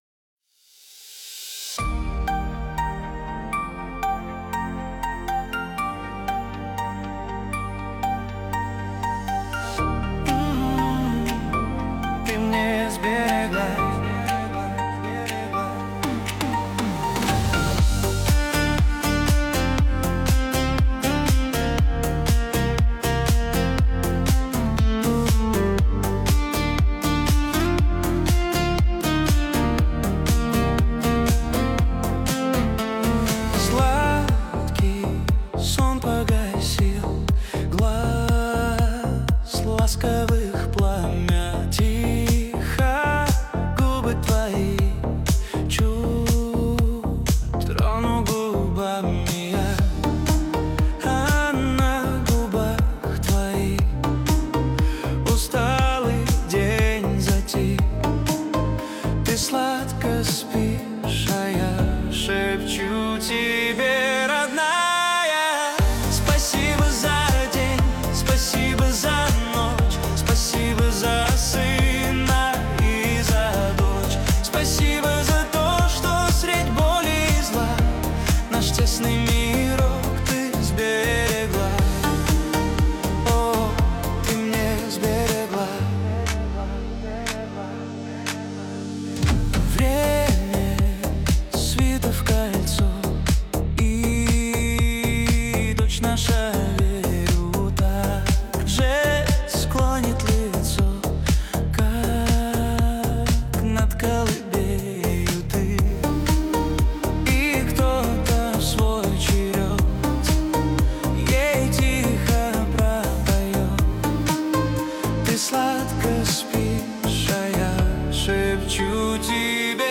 Каверы 2025